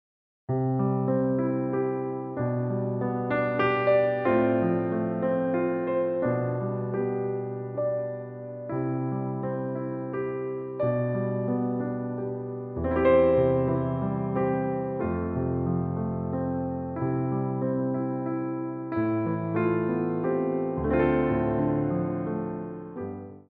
Frappés
4/4 (16x8)